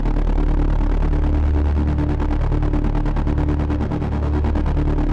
Index of /musicradar/dystopian-drone-samples/Tempo Loops/140bpm
DD_TempoDroneA_140-D.wav